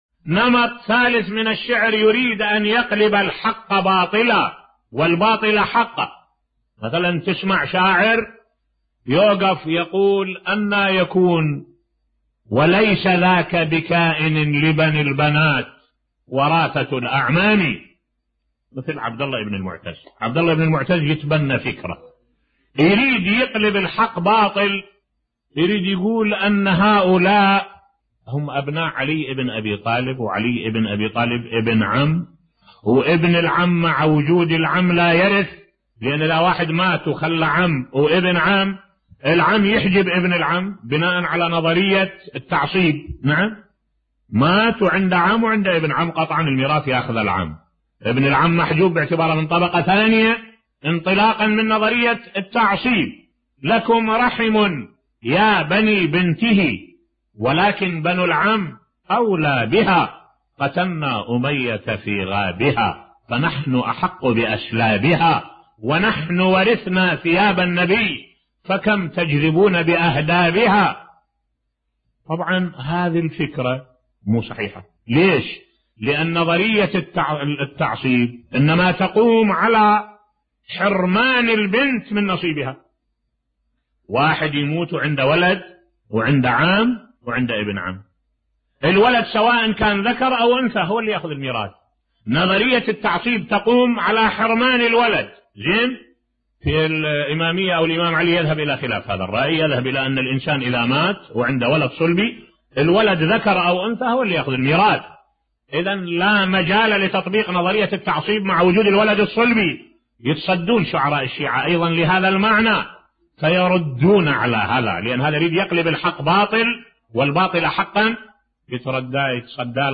ملف صوتی رد صفي الدين الحلي الرائع على ابن المعتز بصوت الشيخ الدكتور أحمد الوائلي